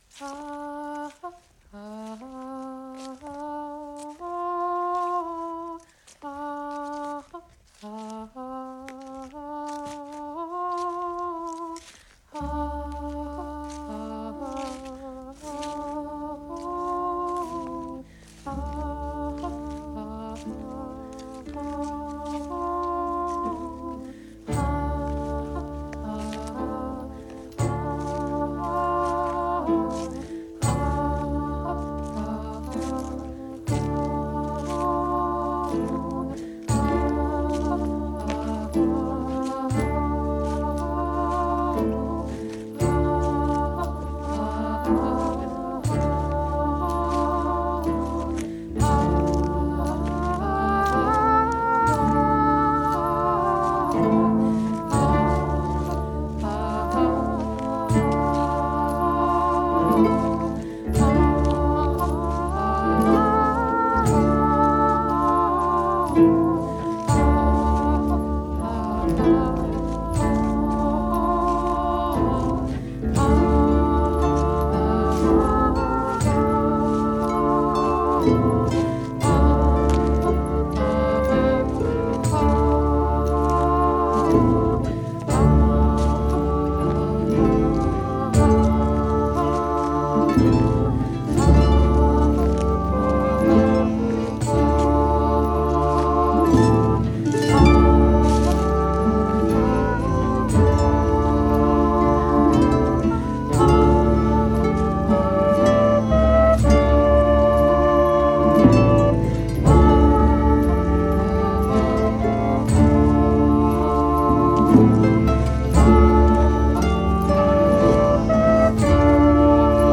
牧歌的なフォークと神秘的なアンビエントが、美しく溶け合ったような素晴らしい作品に仕上がっています！
全ての音が美しいハーモニーとなって聴き手に安らぎを与えてくれる大傑作です！